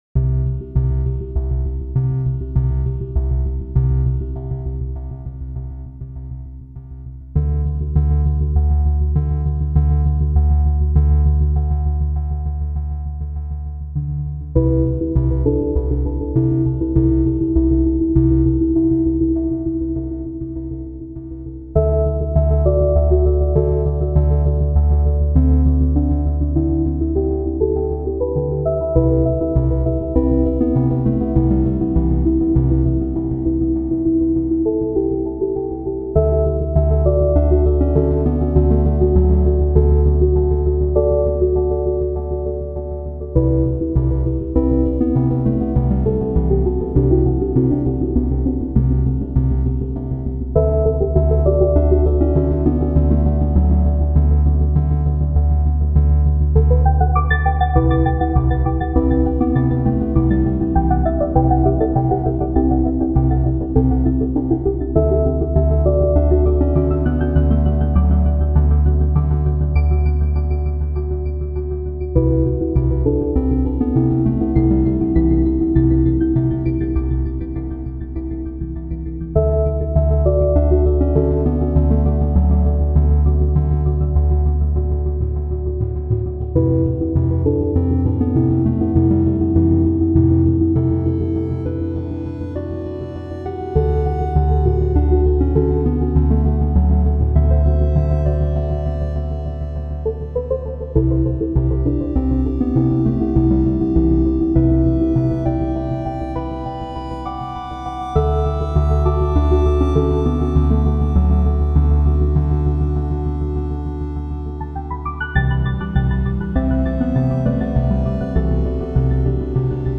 I maximized the volume to CD level. A diaphanous, faintly Kirk Degiorgio-esque synth confection, very nice, that can in no way be blamed for what came later.